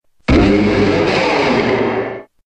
Grito de Mega-Abomasnow.ogg
Grito_de_Mega-Abomasnow.ogg.mp3